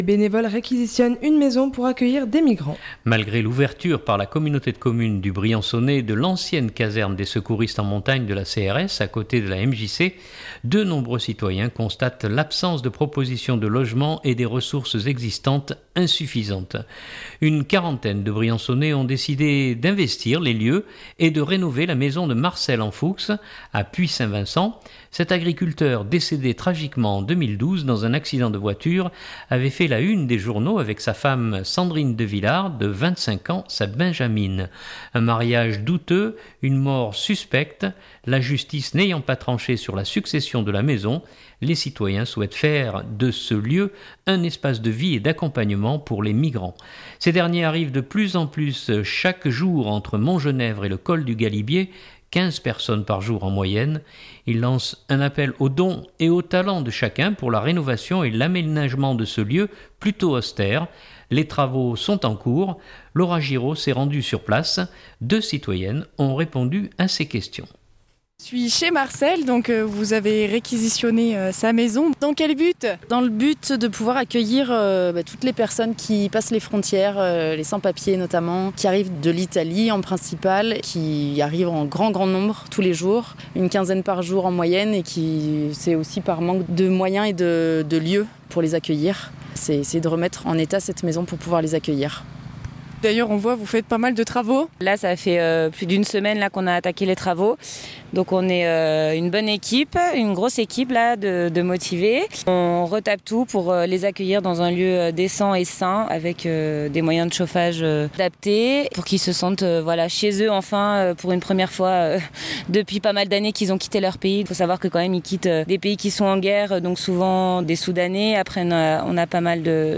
Deux citoyennes ont répondu à ses questions.